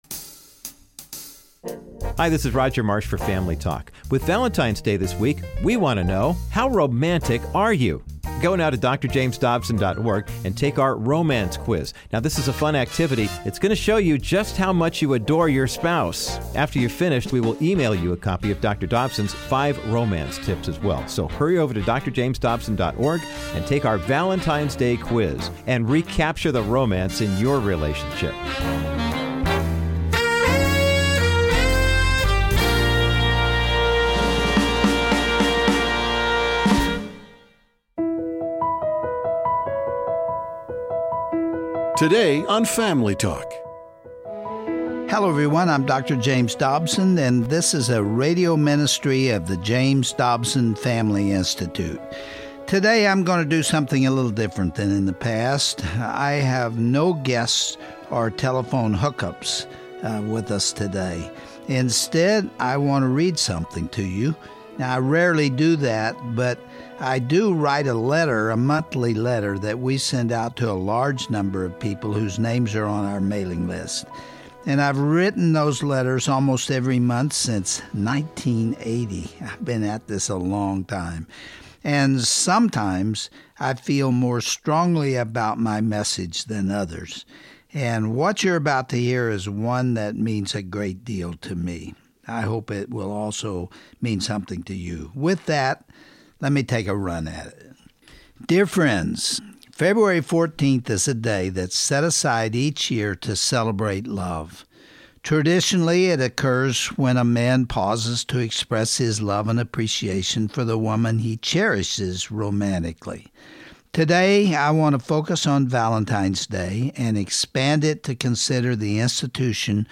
On this exclusive Valentines Day broadcast of Family Talk, Dr. Dobson will read his monthly newsletter about the sanctity of a Godly marriage. He will identify the various attacks on marriage throughout history, and explain how a couple can build a lasting bond.